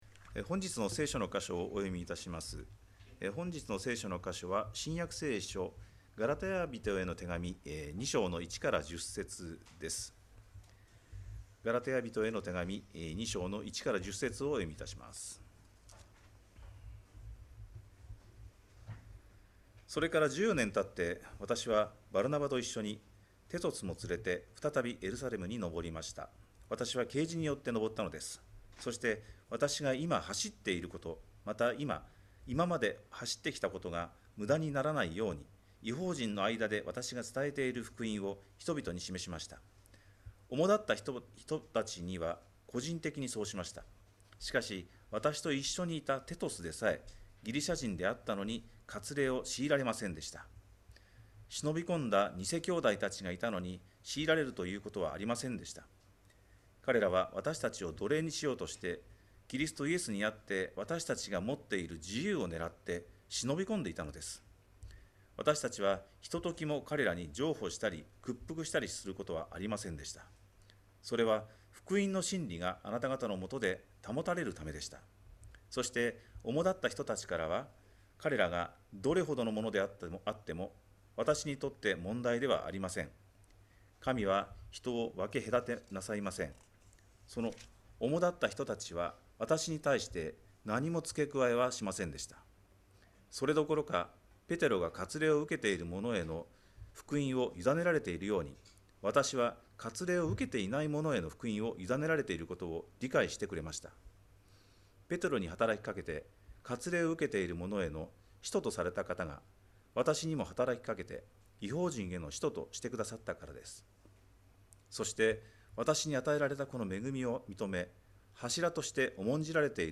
2025年7月13日礼拝 説教 「負けられない戦い」 – 海浜幕張めぐみ教会 – Kaihin Makuhari Grace Church